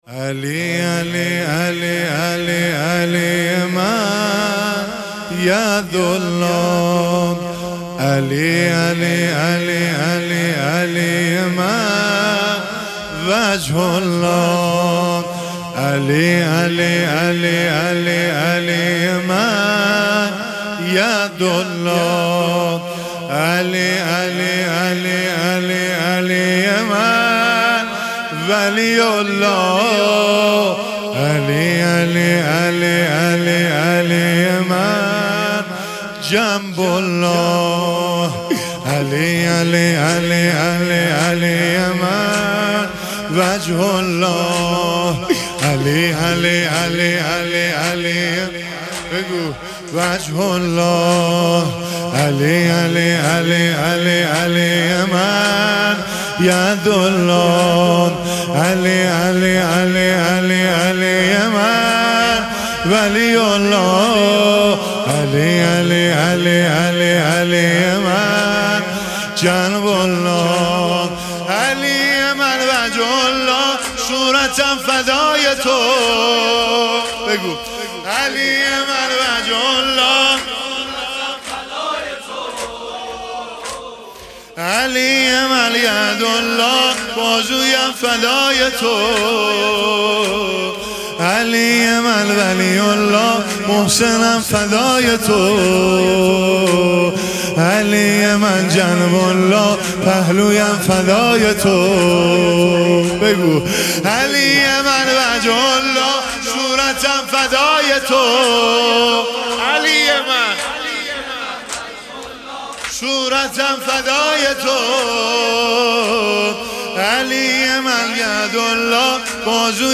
غمخانه بانو ام البنین سلام الله علیها
شهادت حضرت زهرا سلام الله علیها 1443 (ه ق)